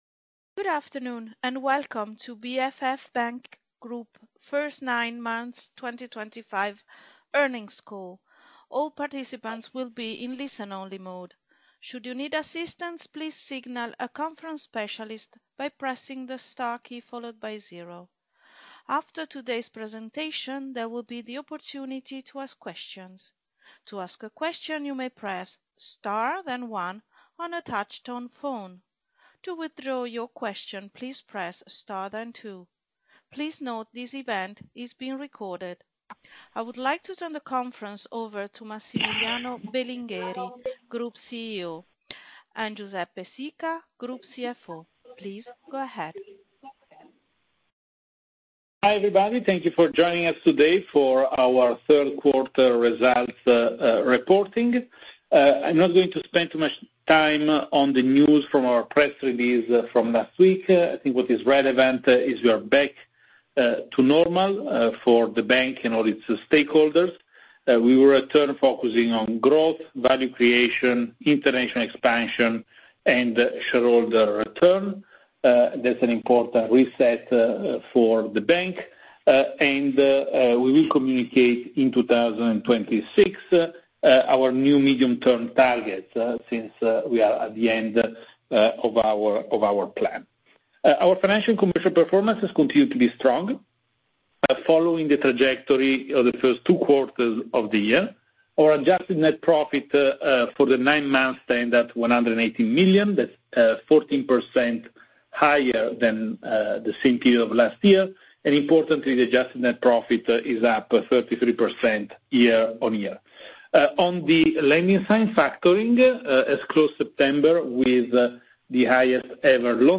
read more Earnings call recording